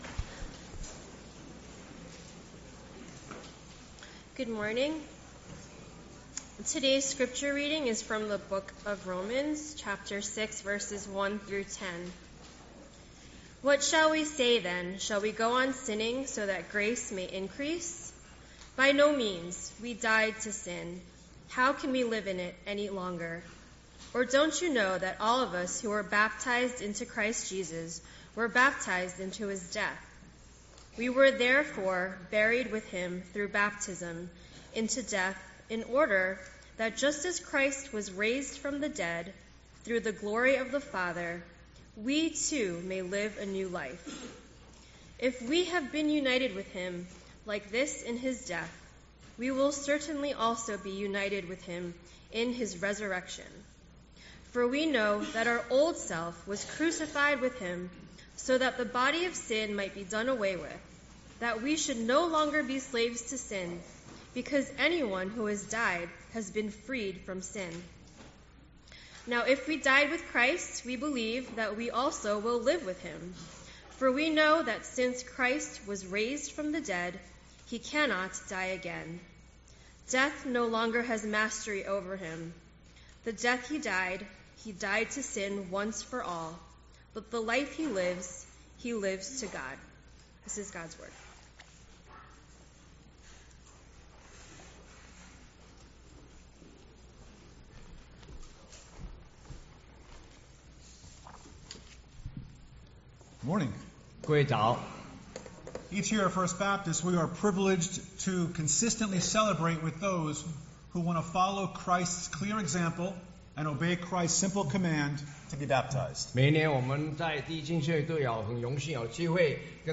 English Sermons | First Baptist Church of Flushing